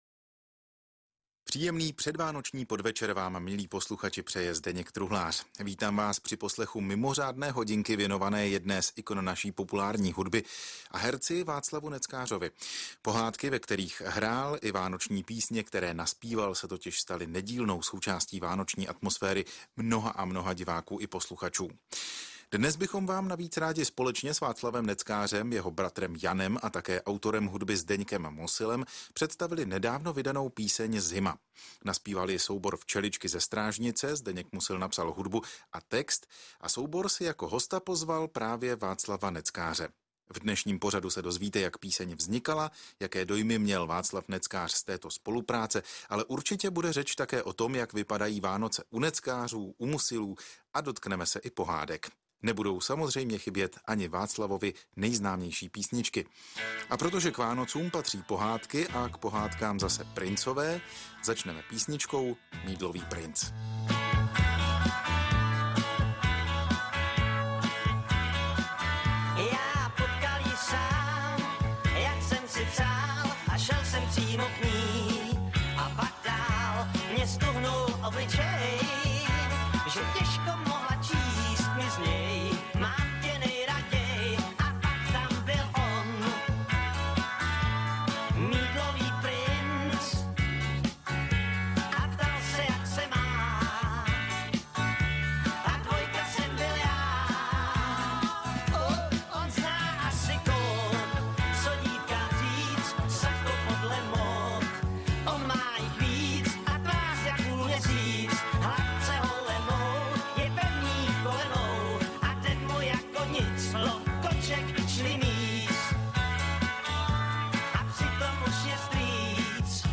Rozhovor v Českém rozhlasu Brno